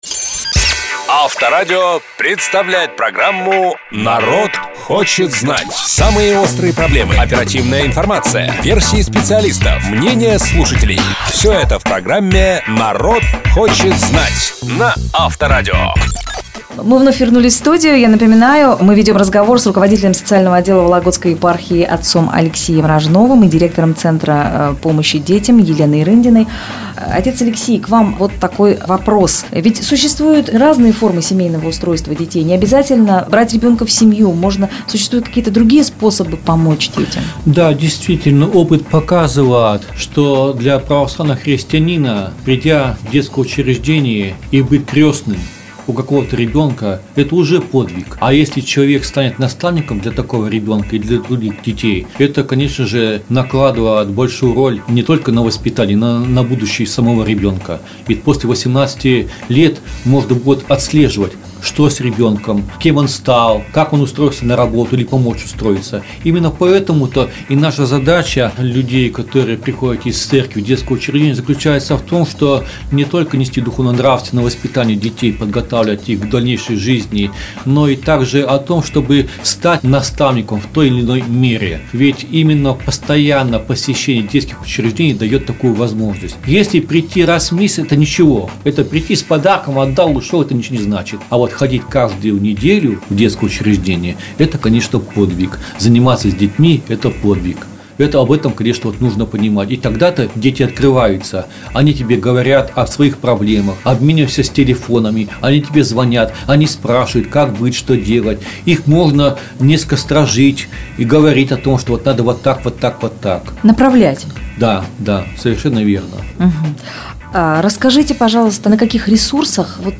Гости студии рассказали о сотрудничестве Департамента социальной защиты населения области и Вологодской епархии в направлении устройства в семью детей, оставшихся без попечения родителей. Во время разговора были подняты вопросы поиска приемных родителей для детей трудноустраиваемых в семью категорий: подростков, братьев и сестер (сиблингов), детей с ограниченными возможностями здоровья.